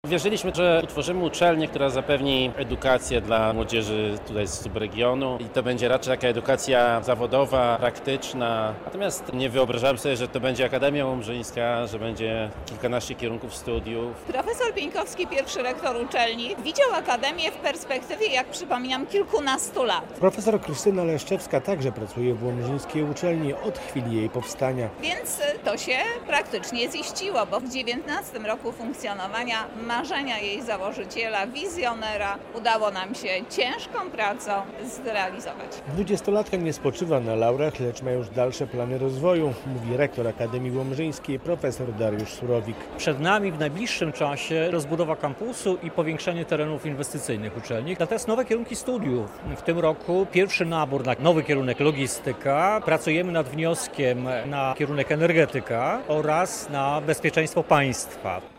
Jubileusz XX-lecia Akademii Łomżyńskiej - relacja